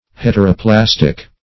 Meaning of heteroplastic. heteroplastic synonyms, pronunciation, spelling and more from Free Dictionary.
Search Result for " heteroplastic" : The Collaborative International Dictionary of English v.0.48: Heteroplastic \Het`er*o*plas"tic\, a. [Hetero- + -plastic.]